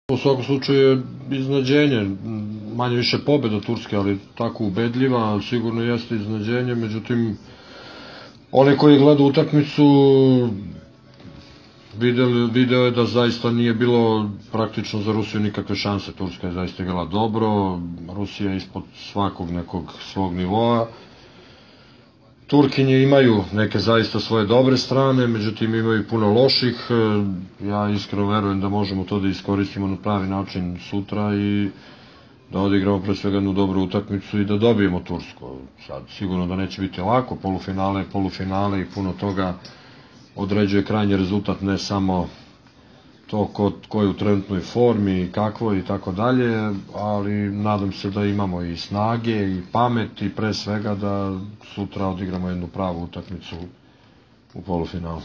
IZJAVA ZORANA TERZIĆA
bk5-terzic.wma